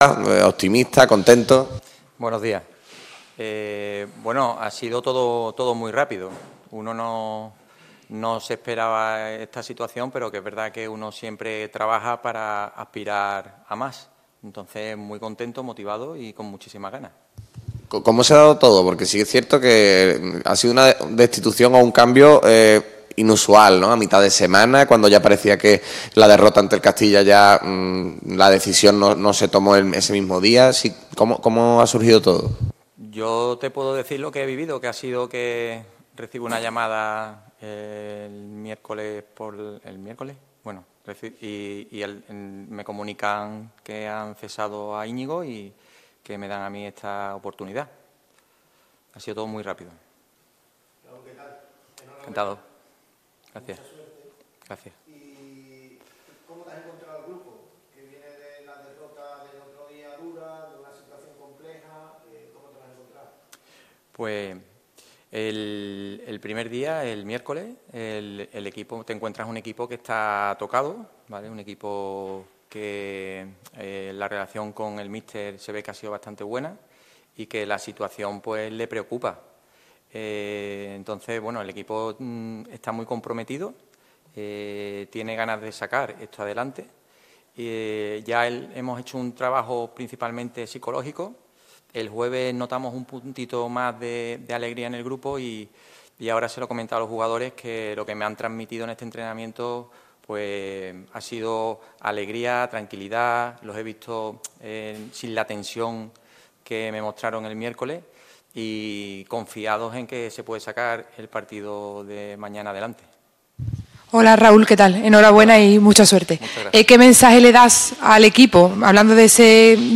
rueda de prensa oficial